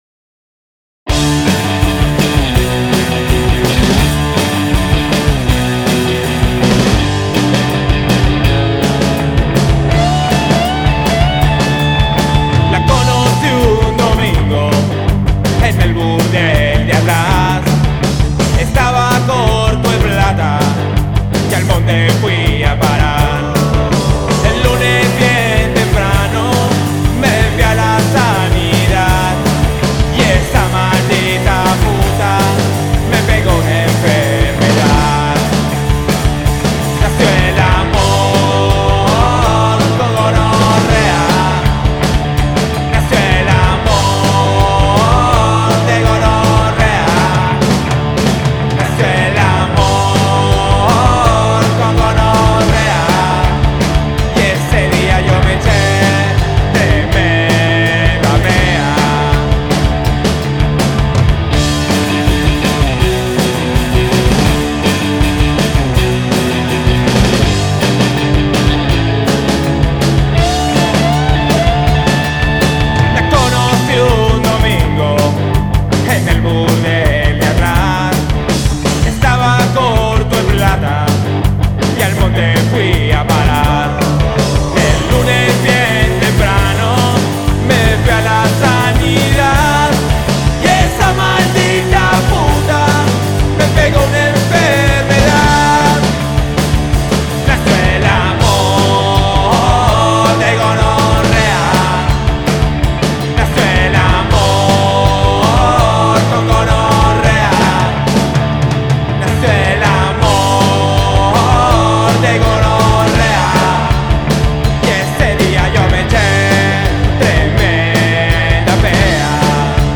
Punk Rock